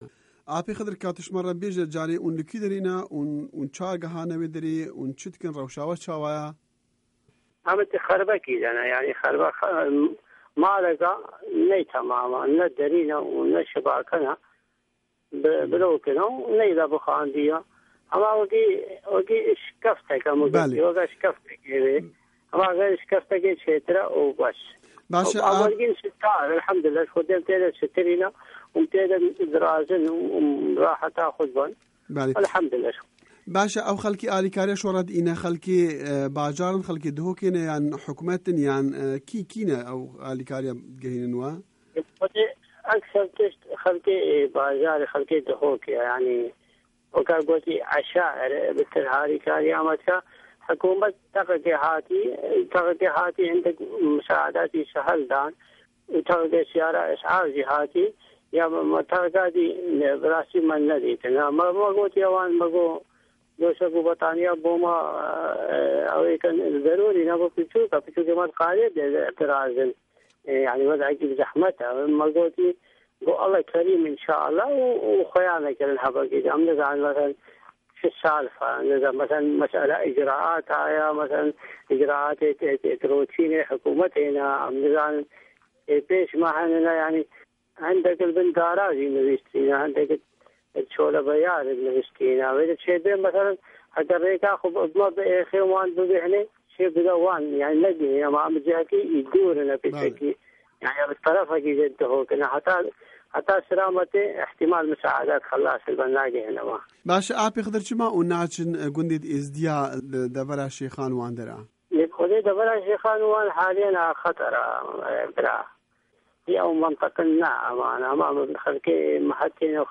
Di hevpeyvîna Dengê Amerîka de hemwelatîyê Êzîdî